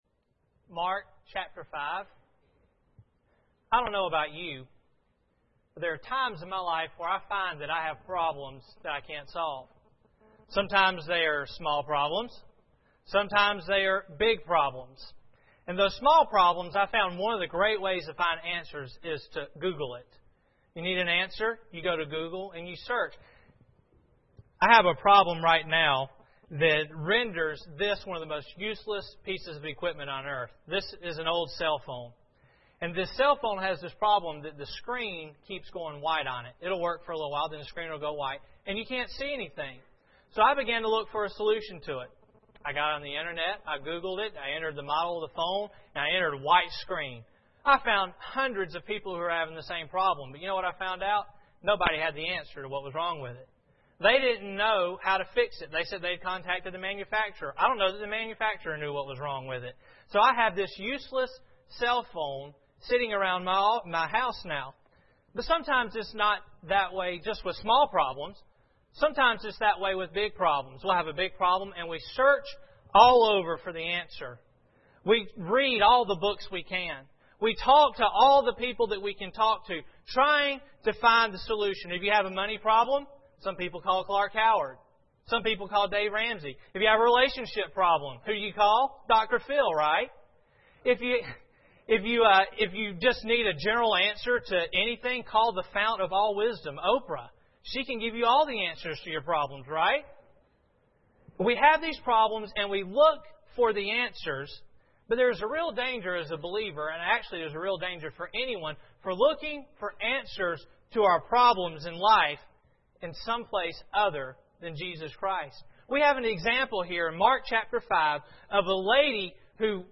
Mark 5:21-34 Service Type: Sunday Morning Bible Text